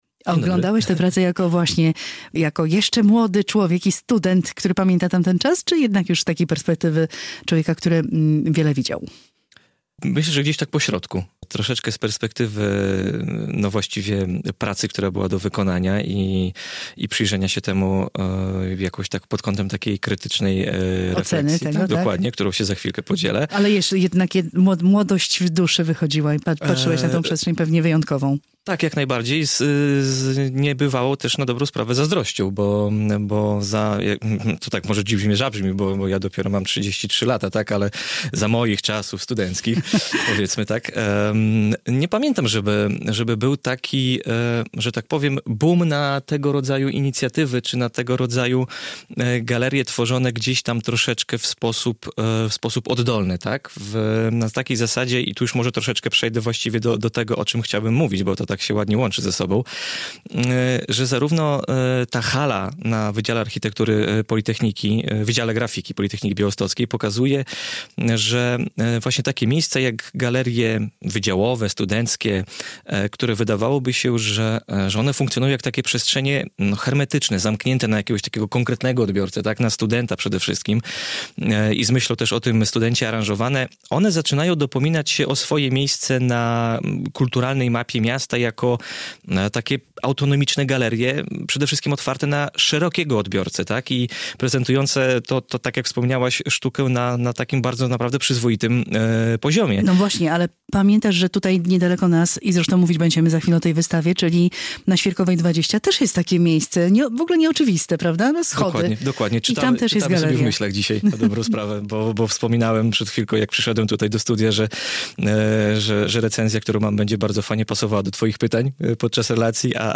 recenzja